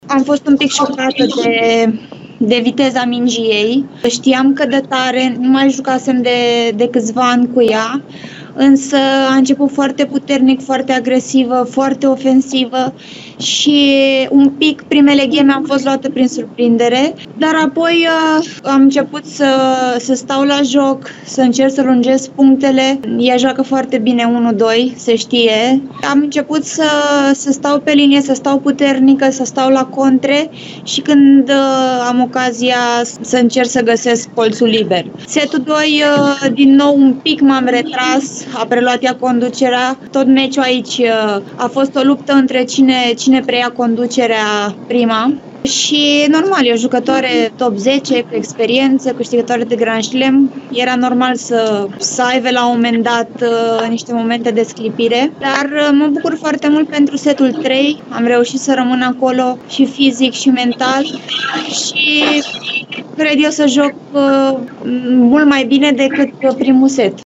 Sorana a vorbit despre meci într-un interviu pentru Eurosport România: